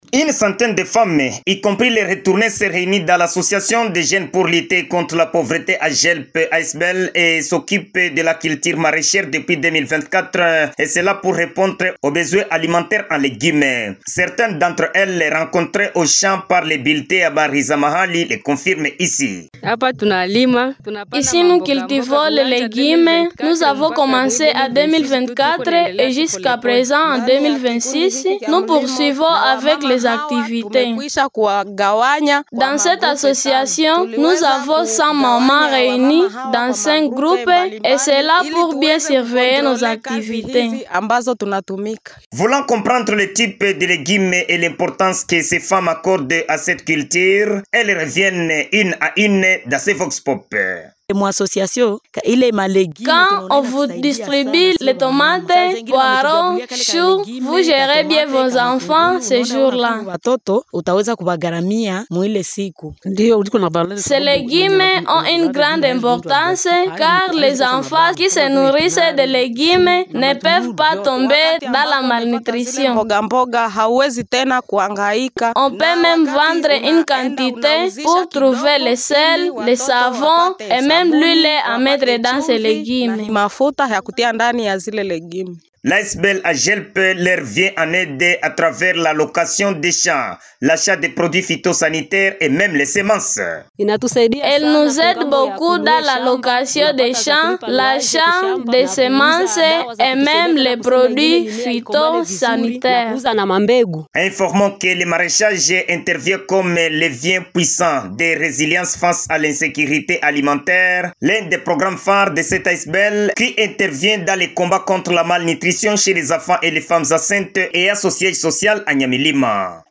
Selon les témoignages recueillis sur place par le Bulletin Habari za Mahali, cette activité a profondément amélioré les conditions de vie des bénéficiaires.